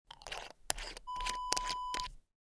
Small Glass Bottle Close Wav Sound Effect #2
Description: The sound of closing a cap of a small glass bottle
Properties: 48.000 kHz 16-bit Stereo
A beep sound is embedded in the audio preview file but it is not present in the high resolution downloadable wav file.
Keywords: small, glass, bottle, metal, cap, lid, close, closing, twist, twisting, pill, tablet, medicine, spice, jar, container
small-glass-bottle-close-preview-2.mp3